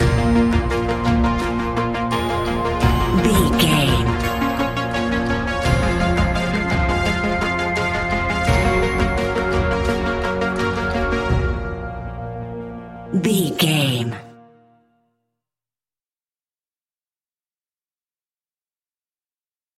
In-crescendo
Thriller
Aeolian/Minor
ominous
dark
haunting
eerie
instrumentals
horror music
Horror Pads
horror piano
Horror Synths